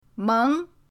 meng2.mp3